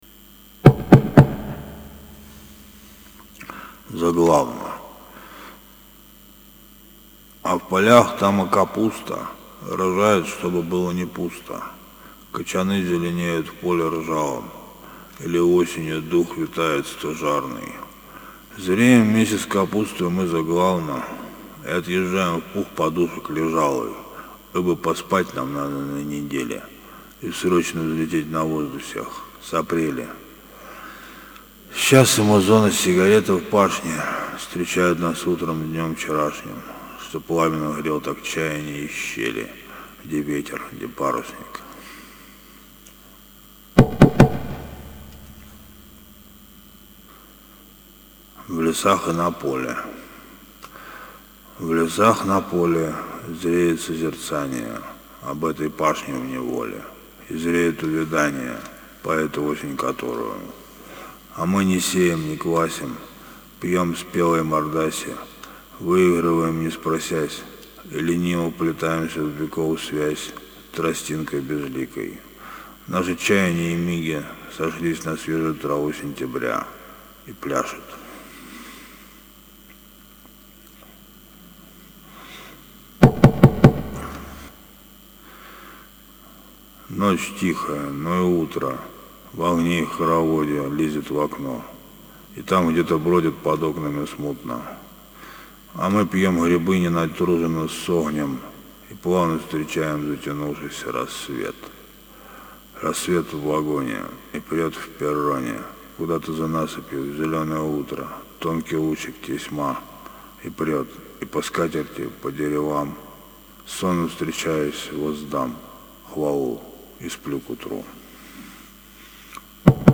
читаю стихи